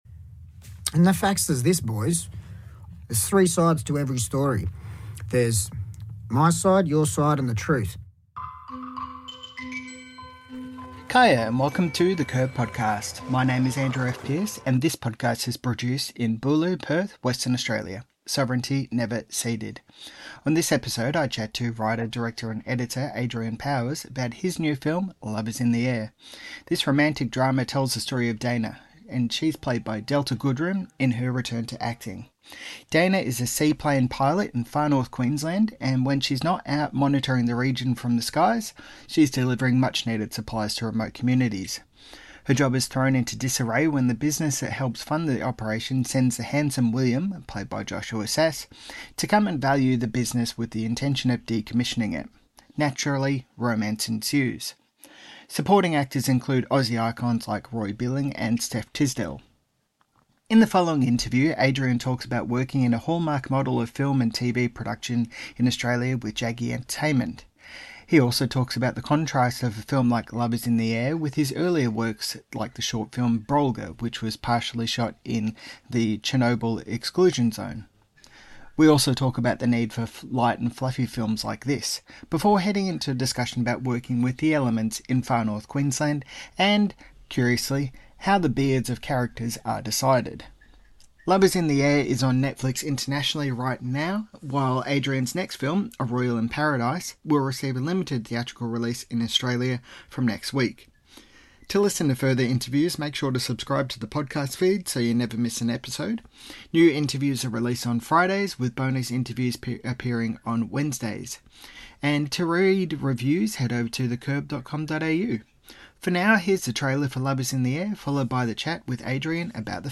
In the following interview